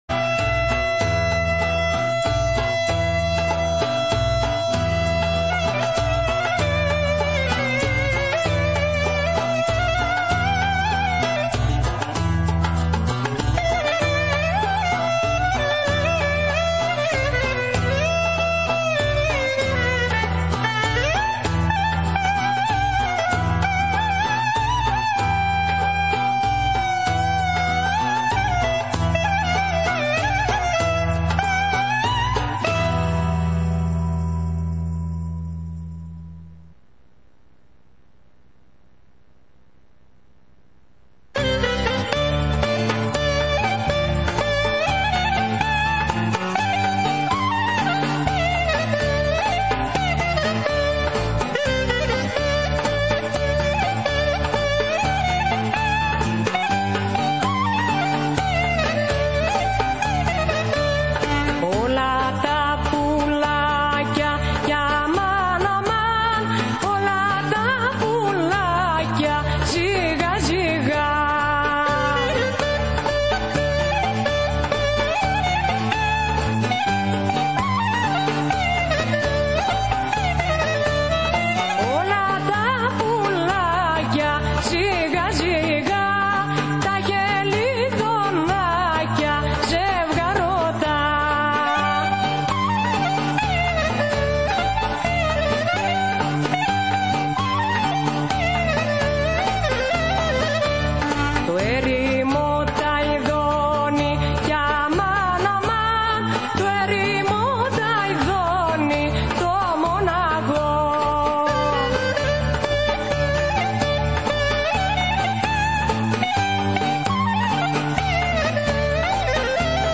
2018-11-22: Ενημέρωση για το μνημόσυνο των 118 εκτελεσθέντων στην Λακωνία και Ρεπορτάζ από την χοροεσπερίδα του Συλλόγου Κρητών Μόντρεαλ